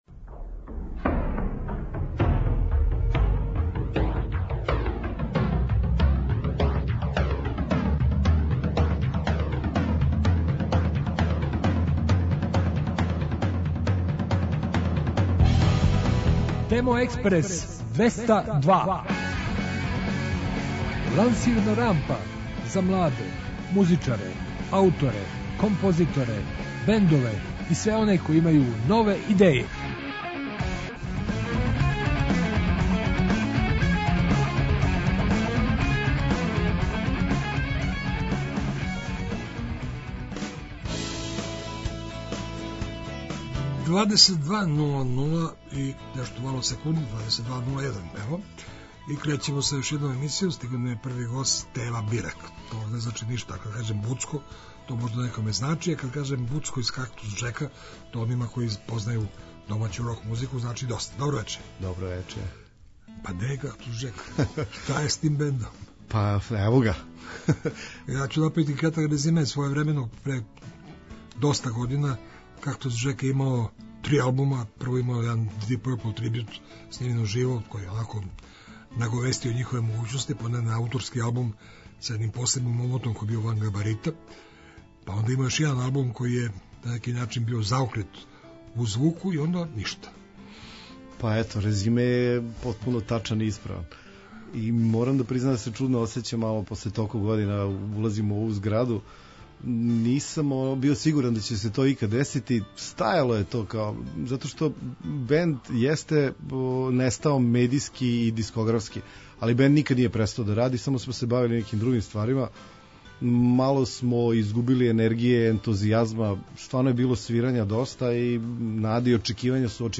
И у овонедељном издању емисије слушамо нове снимке и најављујемо концерте.